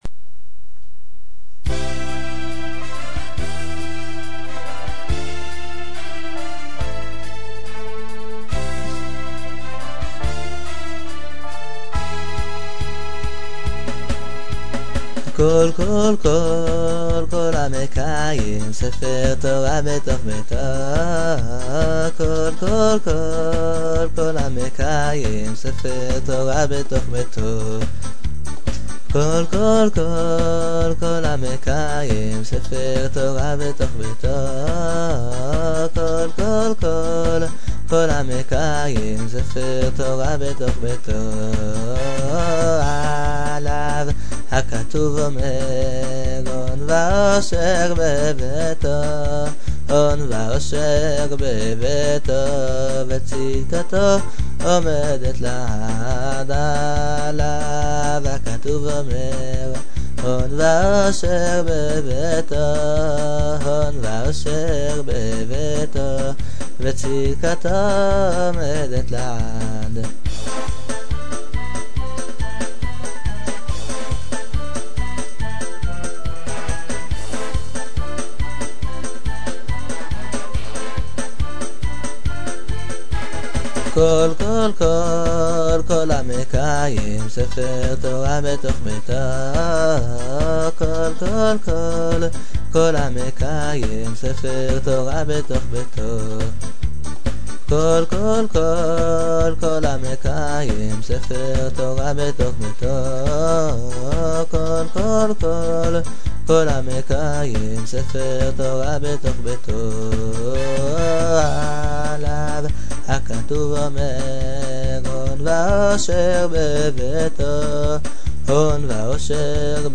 עקב מגבלות המערכת הקובץ דחוס לmp3 ויתכן שהאיכות נפגעה מעט.